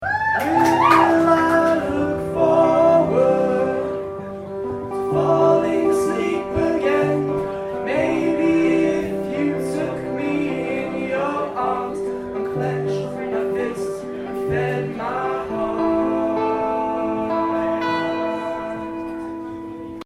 Live at the Revolution Cafe